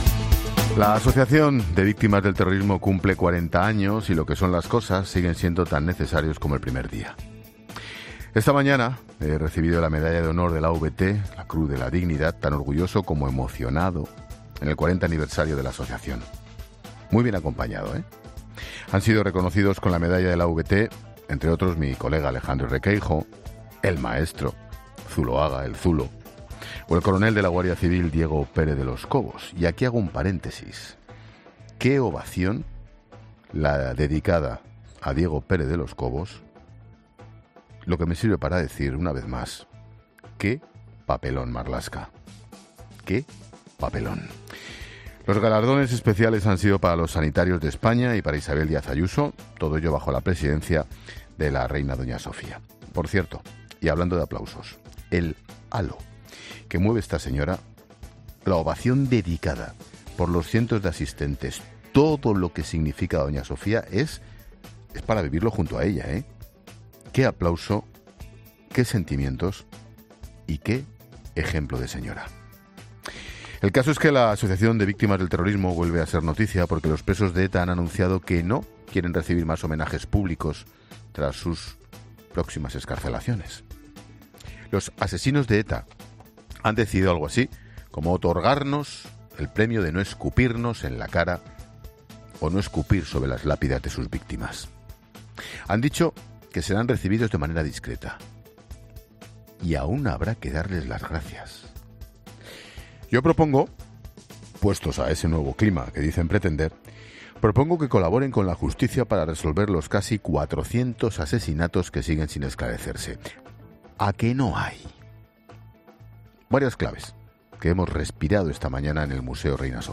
AUDIO: El director de 'La Linterna', Ángel Expósito, habla de la Asociación de Víctimas del Terrorismo y del último anuncio de los presos de ETA
Monólogo de Expósito